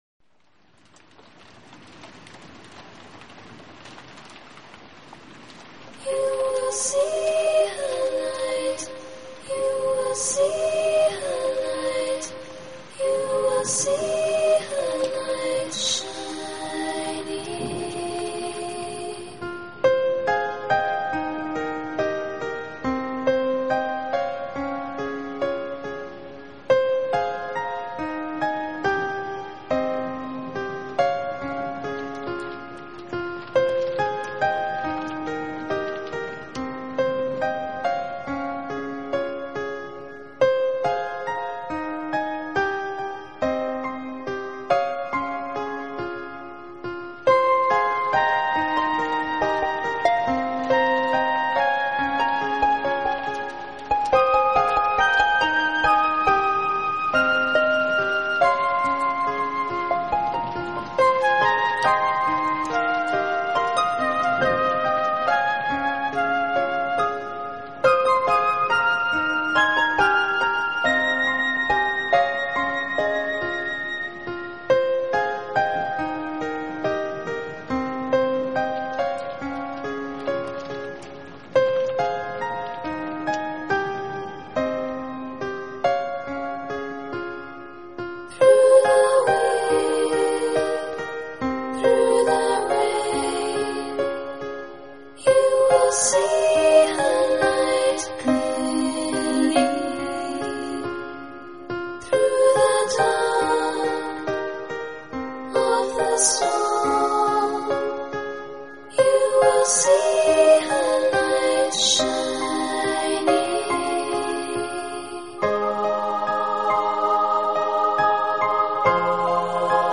【新世纪人声】
梦幻般的编曲，清幽的天籁女声，伴着皎洁的月光，波光粼粼